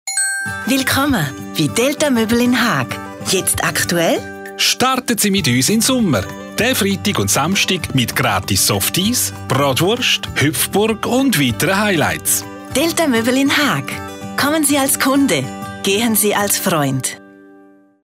Radiospot
Werbespots Radio
MELODY_Radiospot_Delta Möbel.mp3